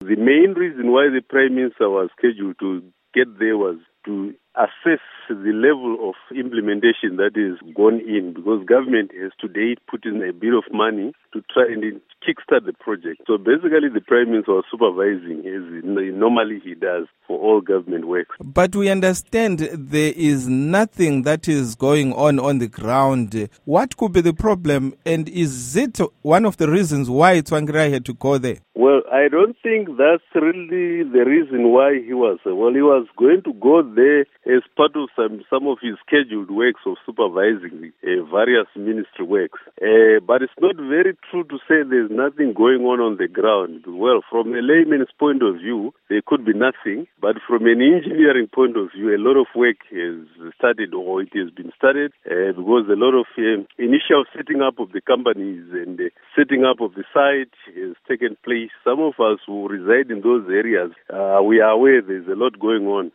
Interview With Joel Gabhuza